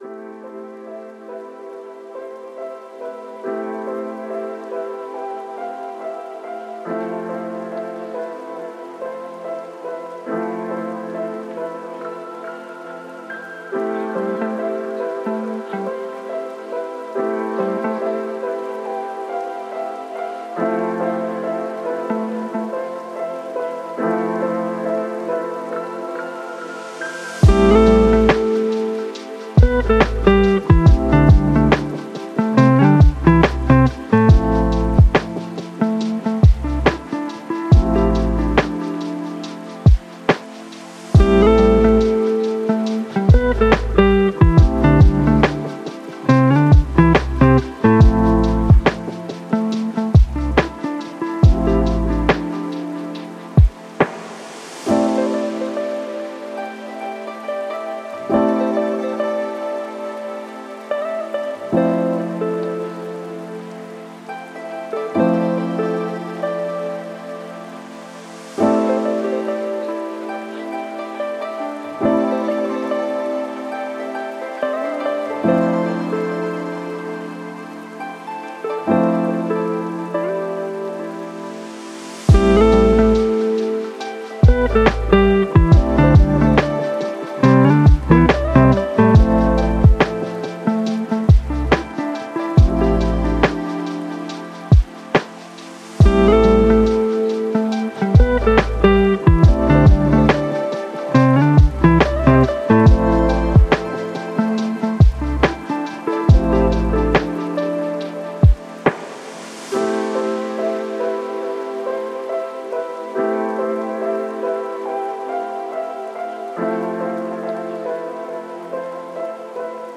Track8_Soothing_Instrumental.mp3